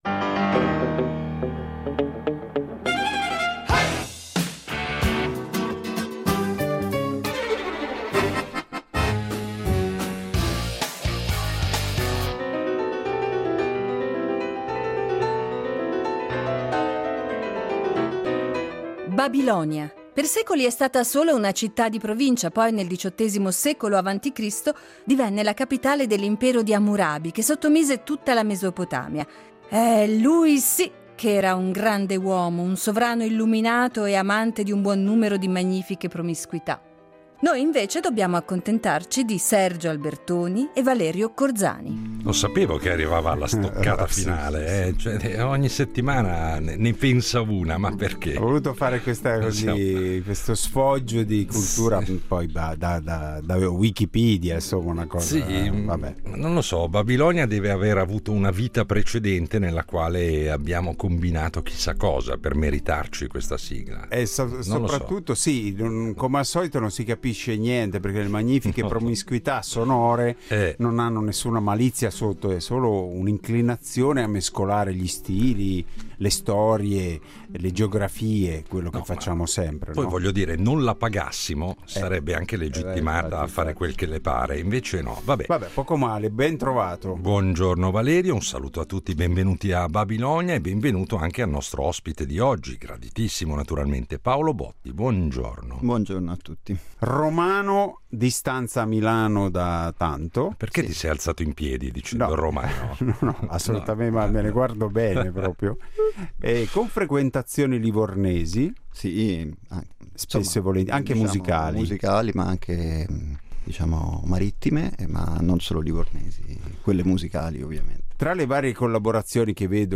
Di questo e di molto altro parleremo, tra una sorpresa e l'altra della nostra imprevedibile scaletta musicale.